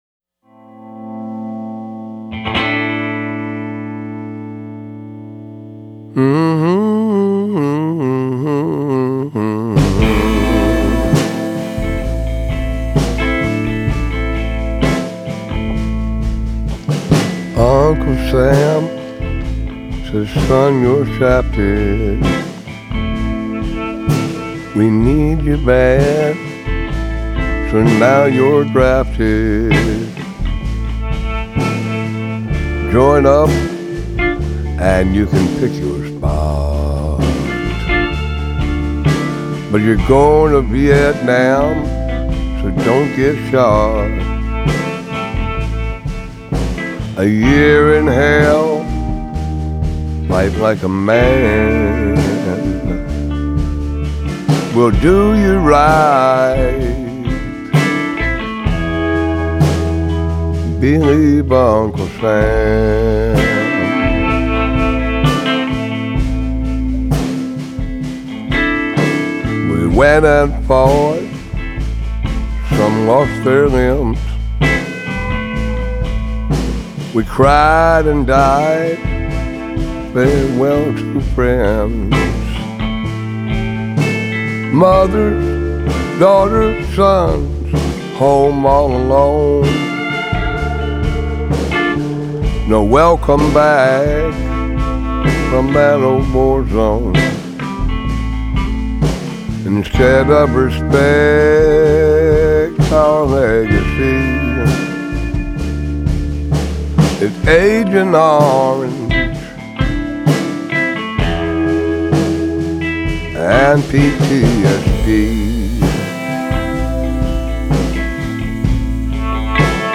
vocals/harmonica/slide guitar
drums/percussion
upright bass
electric guitar
electric bass